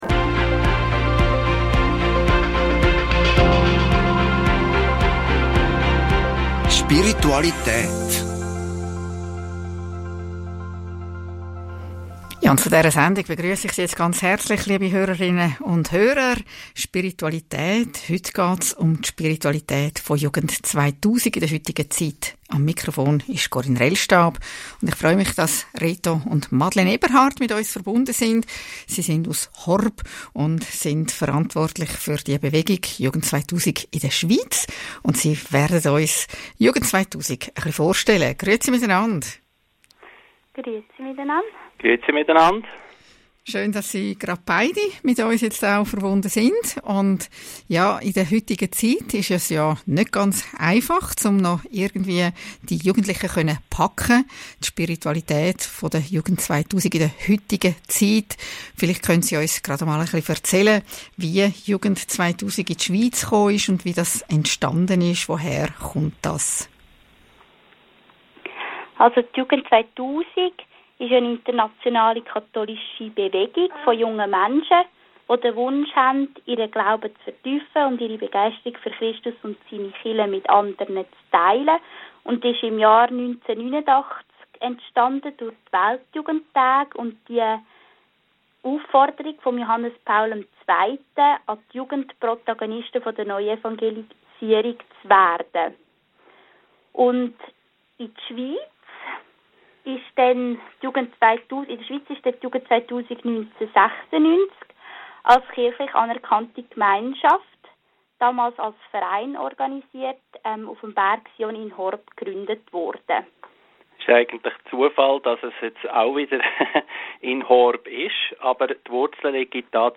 Interview Radio Maria vom 8. April 2019